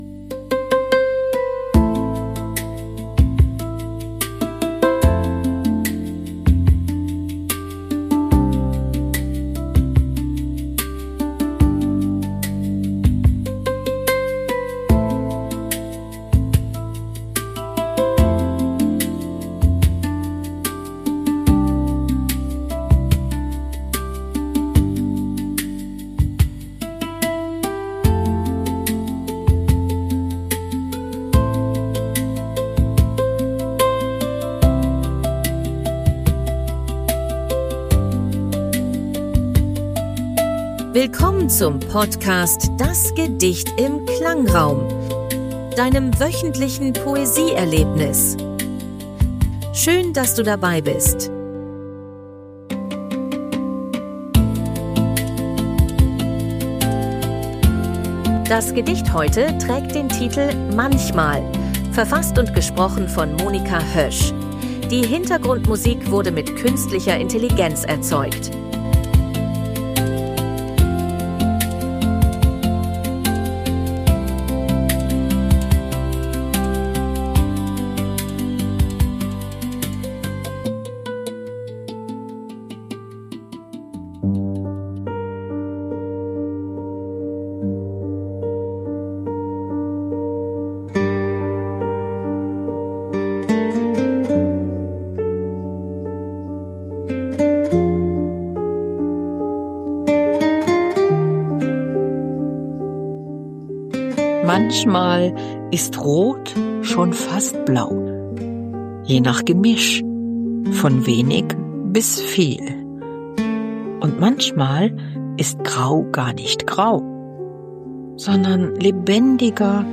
Die Hintergrundmusik wurde mit KI